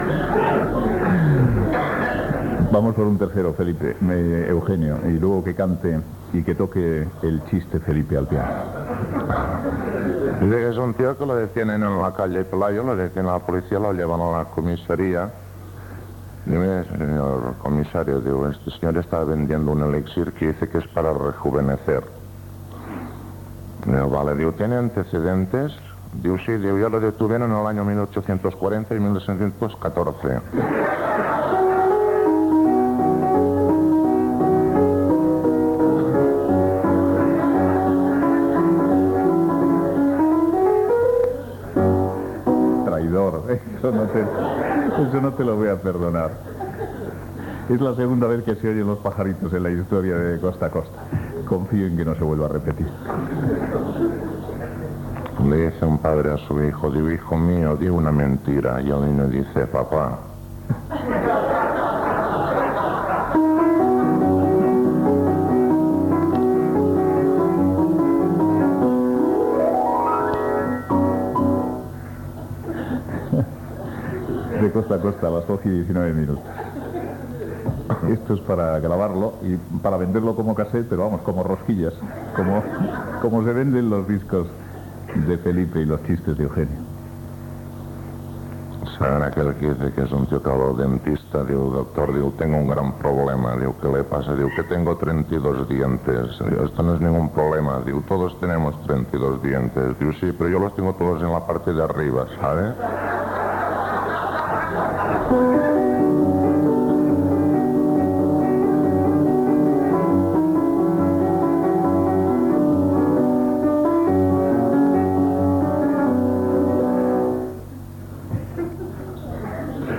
Acudits d'Eugenio i Felipe Campuzano l'acompanya tocant el piano
Info-entreteniment